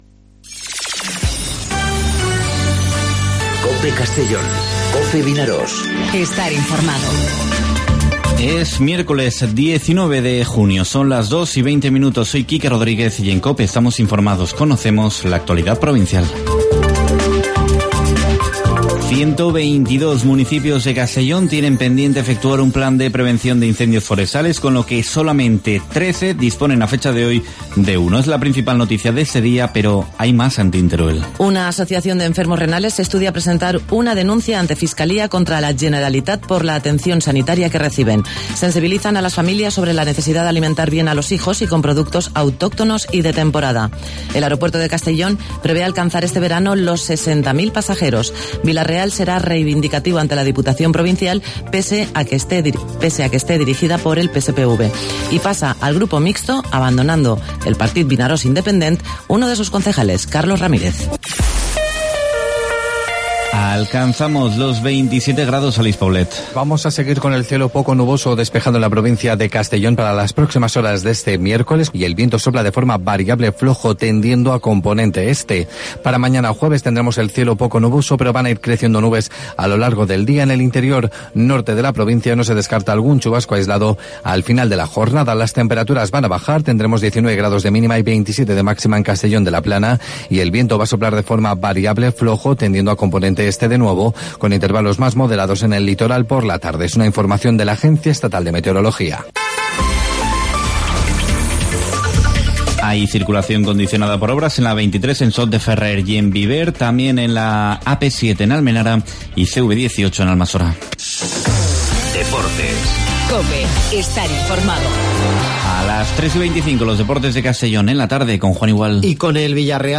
Informativo 'Mediodía COPE' en Castellón (19/06/2019)